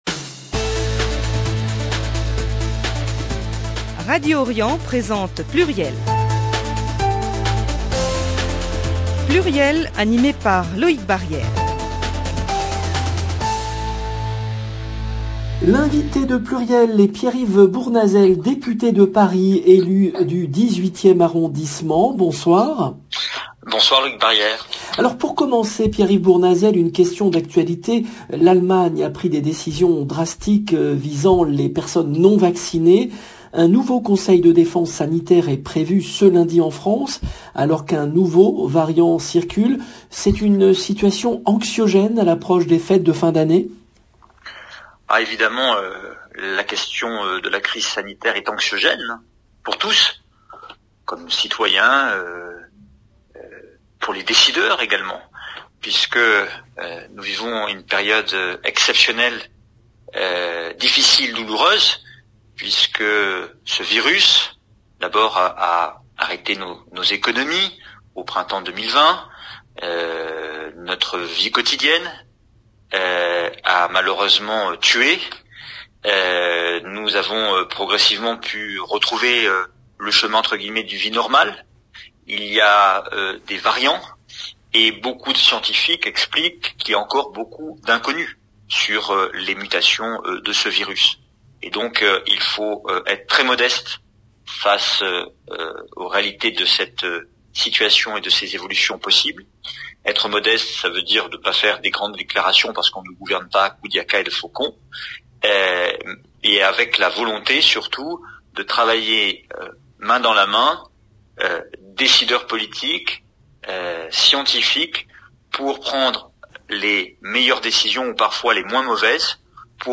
L’invité de PLURIEL est Pierre-Yves Bournazel, député de Paris, élu du 18e arrondissement